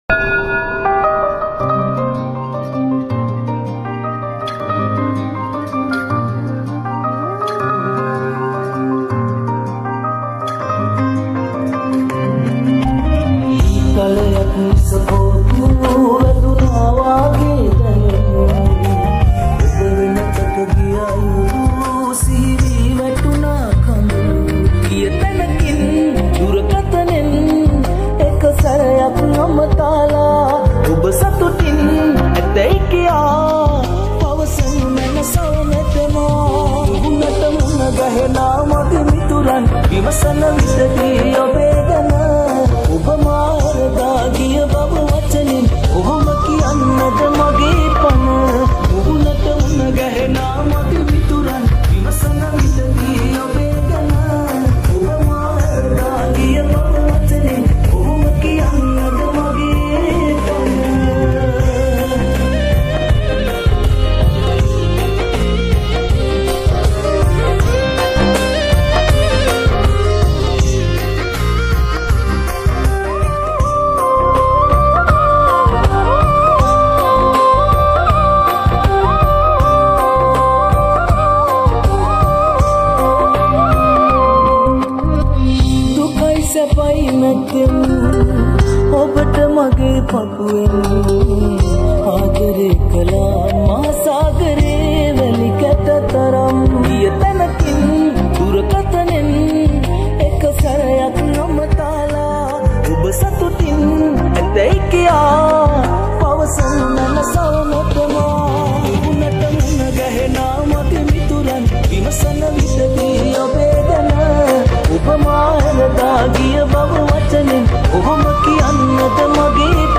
sri 8d music New Song